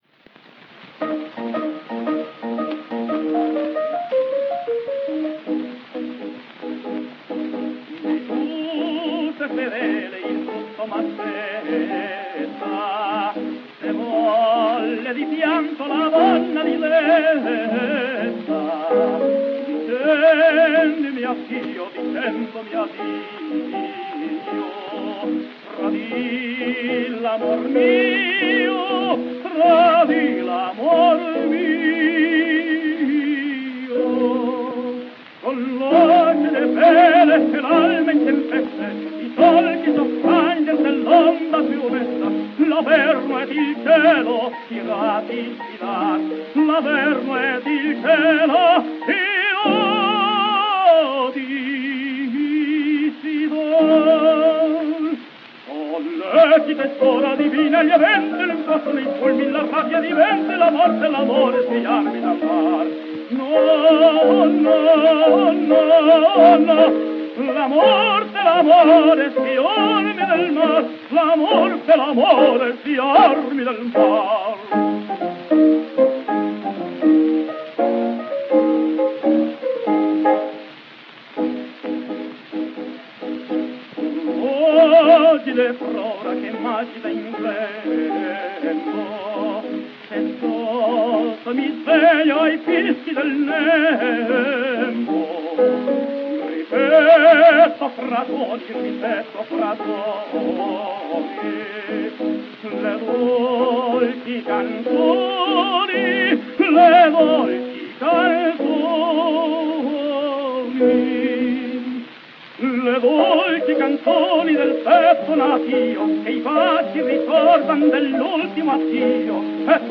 The Catalan tenor
Fonotipia, Milano, 16 October 1906